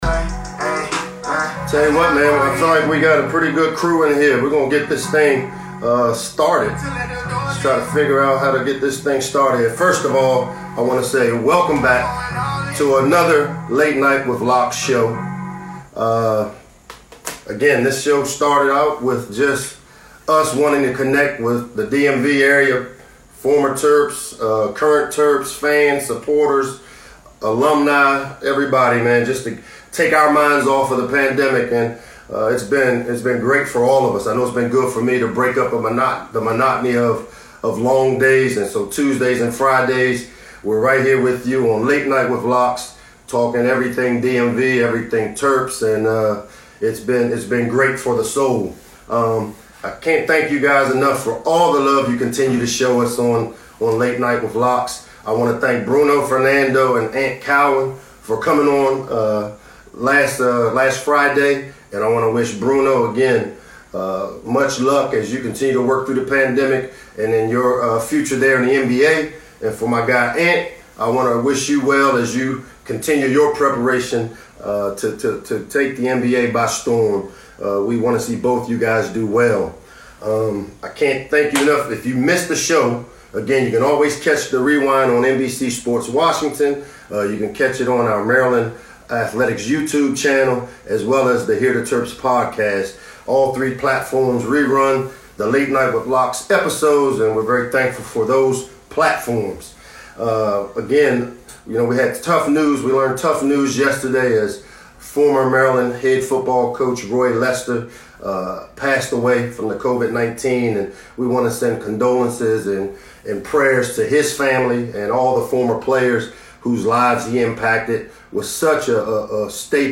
Late Night with Locks is an Instagram live show hosted by head football coach Michael Locksley every Tuesday and Friday evening at 7 p.m. This show featured Pittsburgh Steelers head coach Mike Tomlin & new Steeler safety Antoine Brooks Jr.